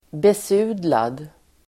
Ladda ner uttalet
besudlad adjektiv (mest bildligt), stained , soiled Uttal: [bes'u:dlar] Böjningar: besudlat, besudlade Synonymer: nedsmutsad, smutsig Definition: nedfläckad Sammansättningar: blod|besudlad (bloodstained)